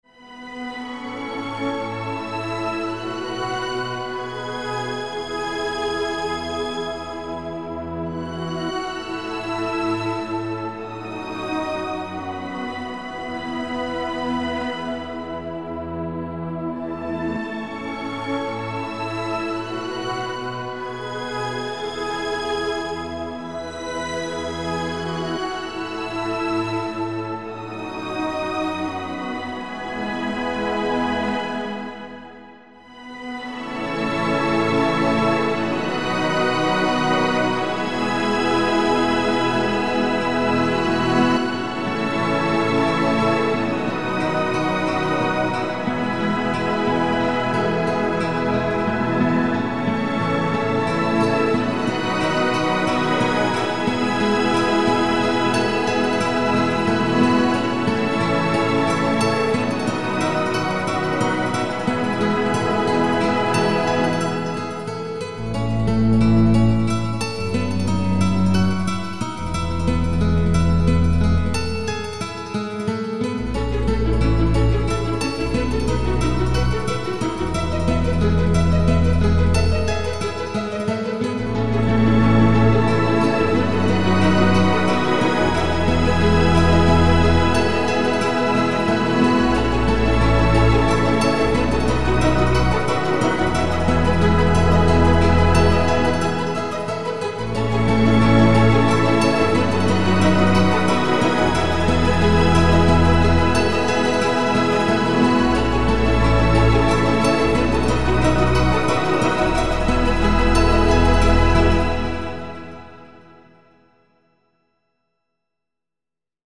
1:56 – Ballad / Symphonic.